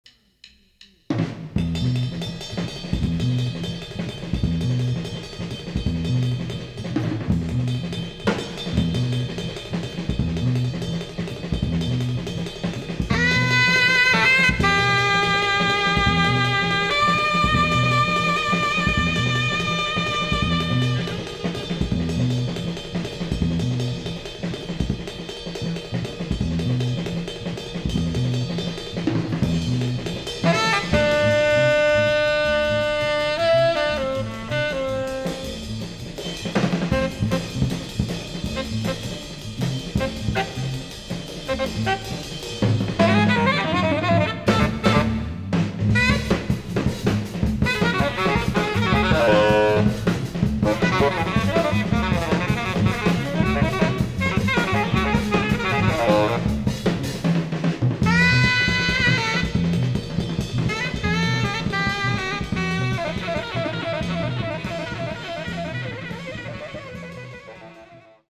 Tenor and Soprano Saxophones
Electric Bass
Drums and Congas